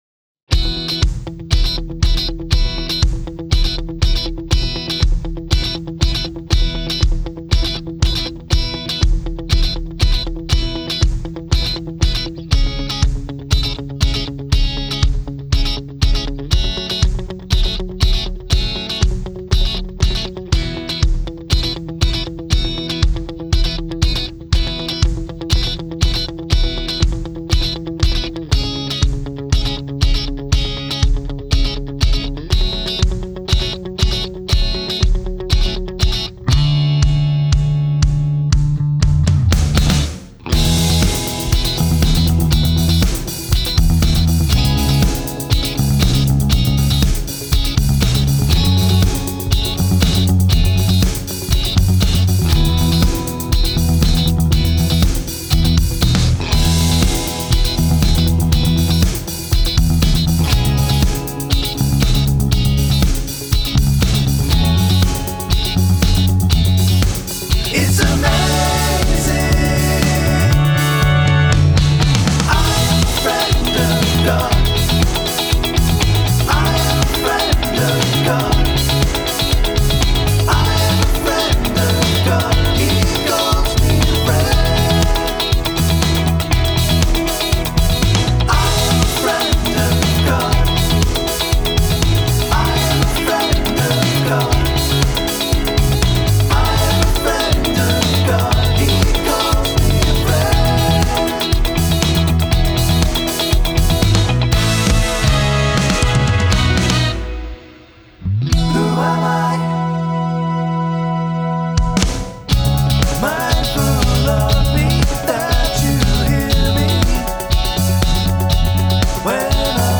Key: E BPM: 120 Time sig: 4/4 Duration:  Size: 14.2MB
Funk Soul Worship